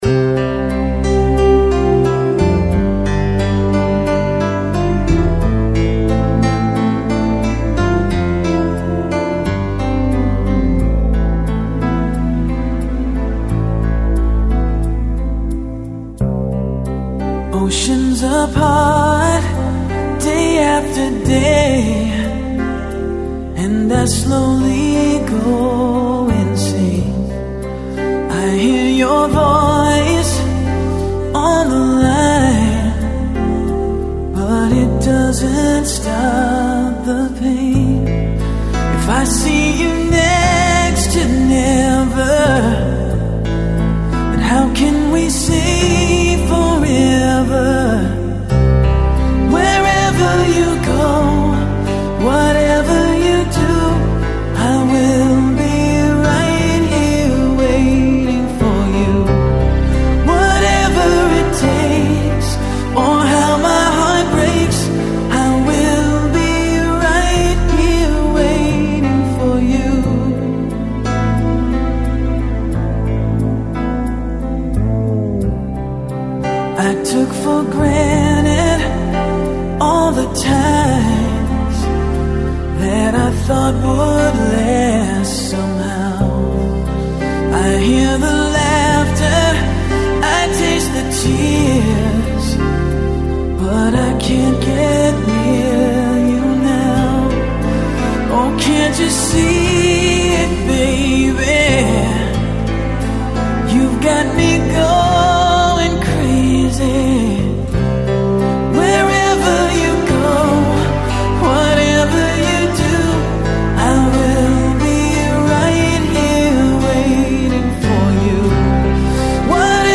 • Категория: Медляки